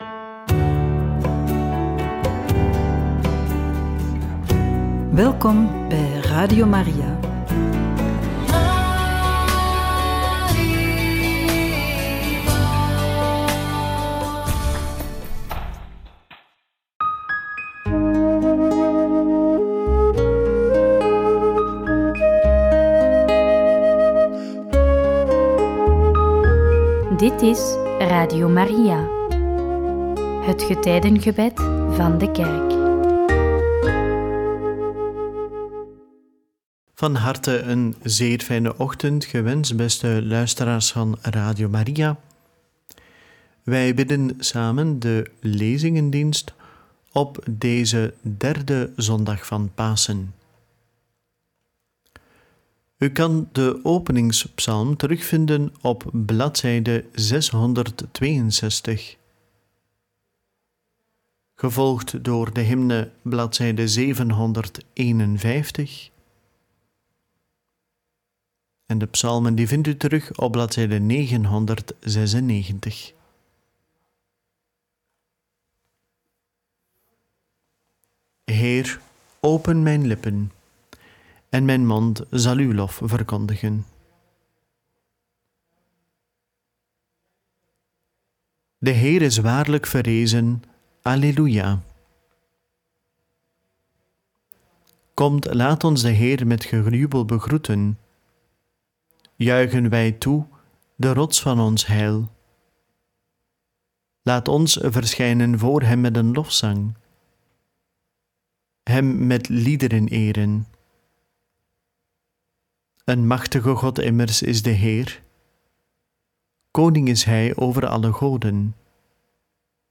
Lezingendienst 19 april 2026 - Derde zondag van Pasen - Pagina's: 662, 751, 996 Play Episode Pause Episode Mute/Unmute Episode Rewind 10 Seconds 1x Fast Forward 30 seconds 00:00 / 26:50 Share Share Link Embed